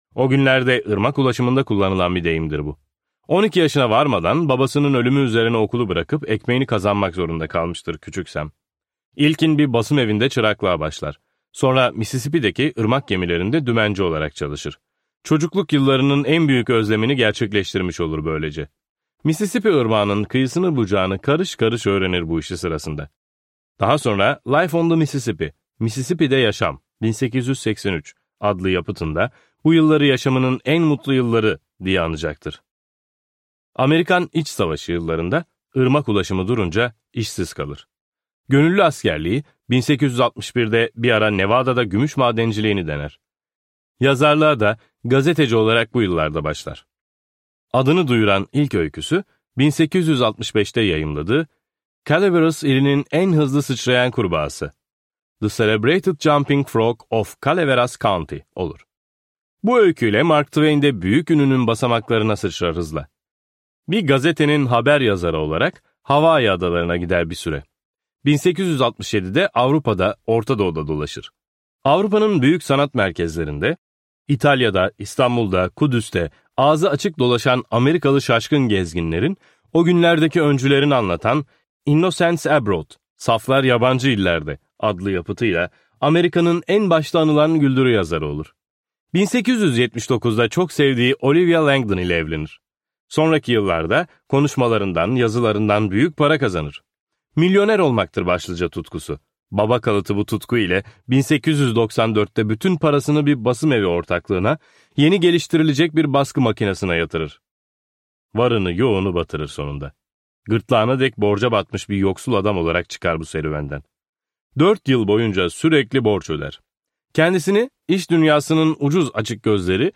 Adem'le Havva'nın Güncesi ve Seçme Öyküler - Seslenen Kitap
Seslendiren